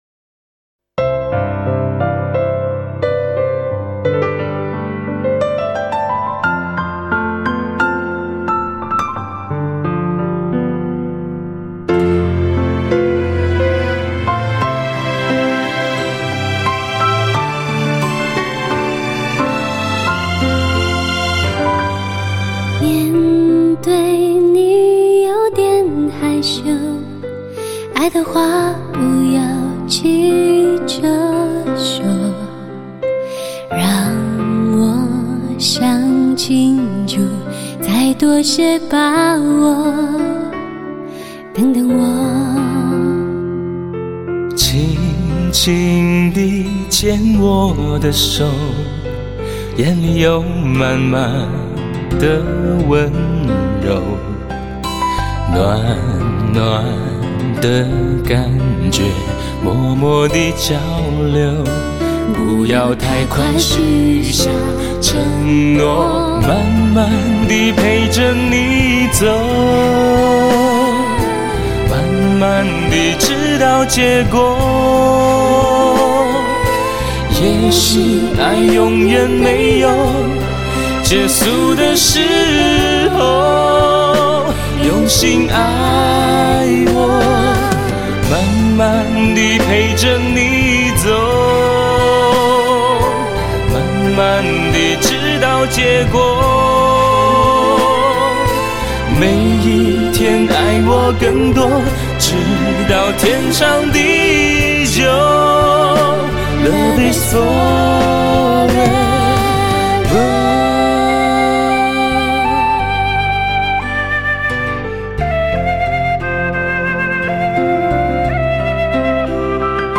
豪华的弦乐群在编配上的大量运用，与中国民族乐器配合得相得益彰。
优美华丽的多声部与和声的演绎，尽显人声与乐器的和谐和美感。
豪华的交响弦乐群与民族乐器的“延伸拉阔”
多声部与和声的丰富层次
交织出丰富而立体的庞大音乐空间格局